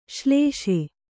♪ ślēṣe